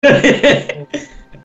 Play CZM LAUGH - SoundBoardGuy
Play, download and share CZM LAUGH original sound button!!!!